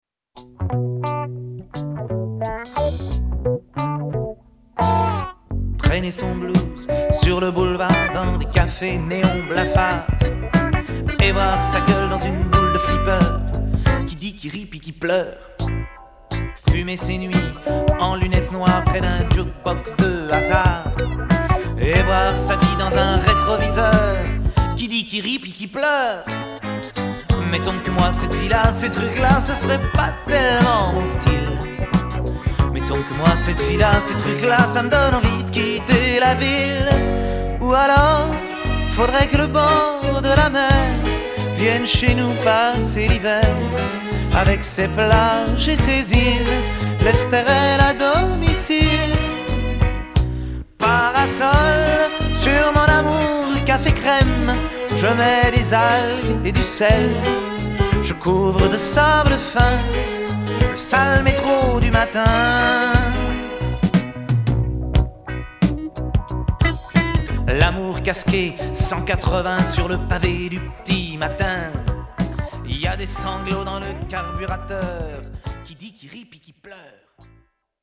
tonalités SI mineur et SI majeur